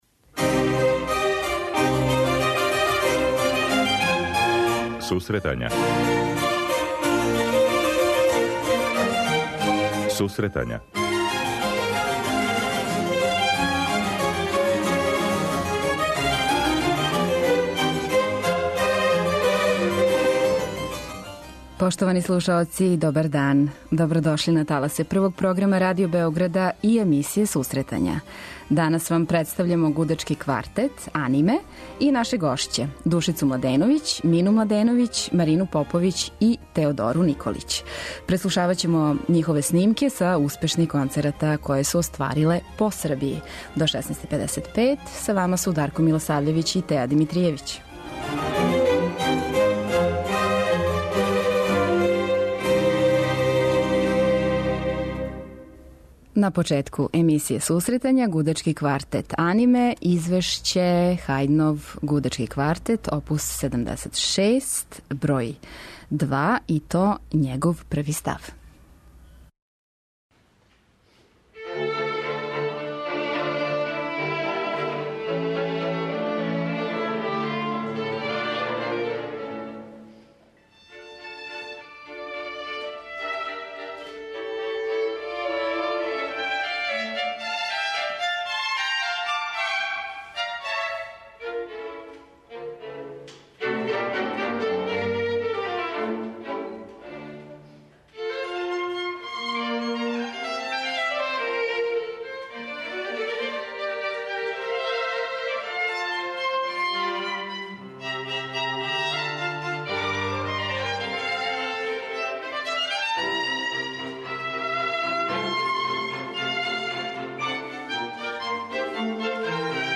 Представљамо вам гудачки квартет 'Аниме'.
Заједно преслушавамо снимке са успешних концерата које су оствариле по Србији.